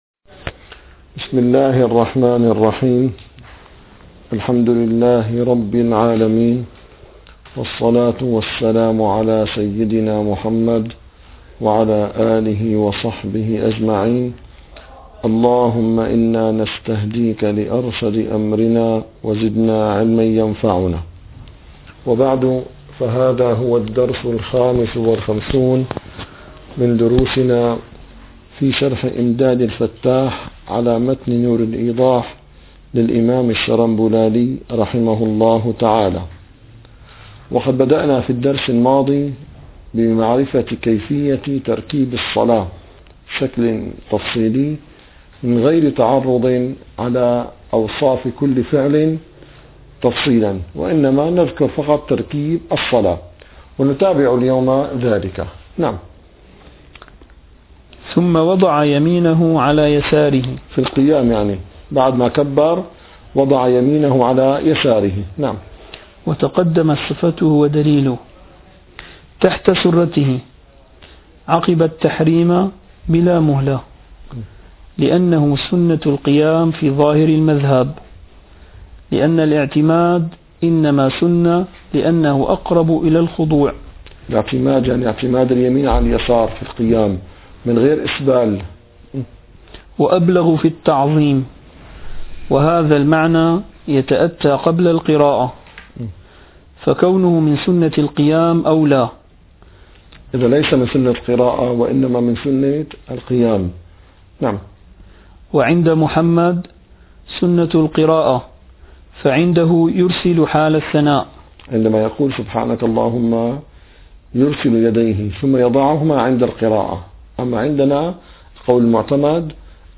- الدروس العلمية - الفقه الحنفي - إمداد الفتاح شرح نور الإيضاح - 55- كيفية تركيب الصلاة